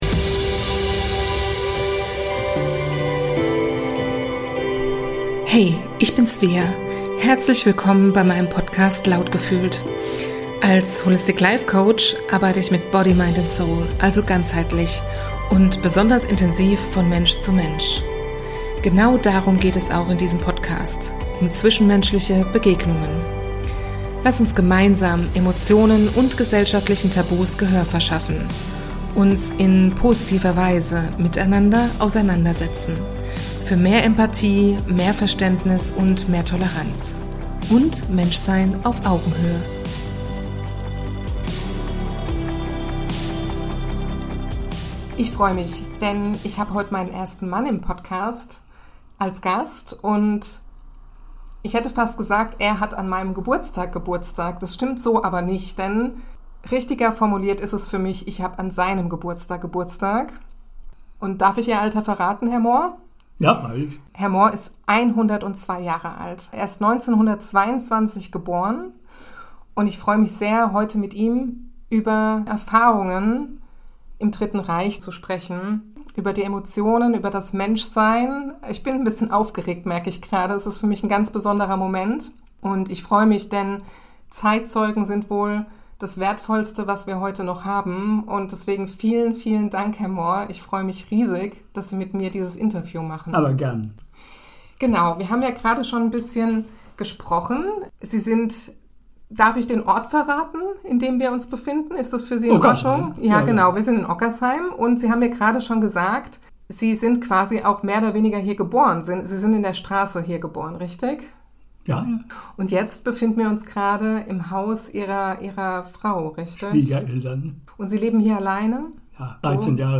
Ein großes Danke von Herzen an diesen beeindruckenden Mann für dieses berührende und bewegende Gespräch.